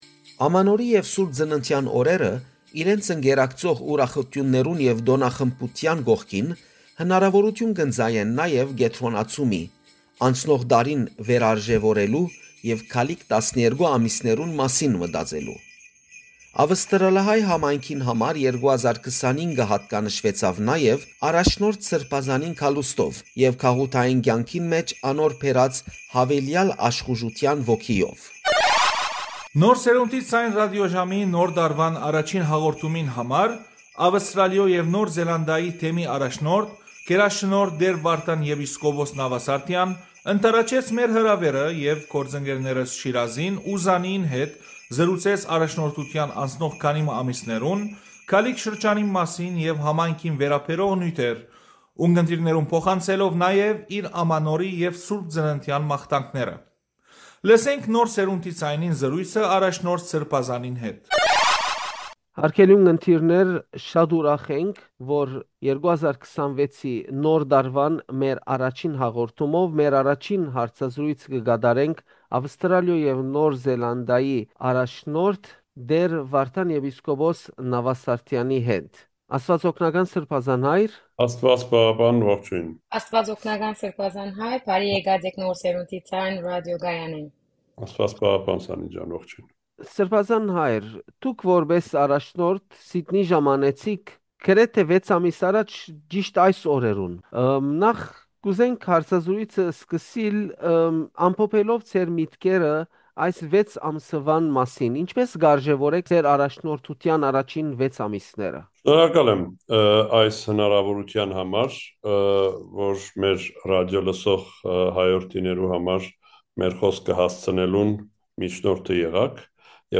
Interview with His Grace Bishop Vardan Navasardyan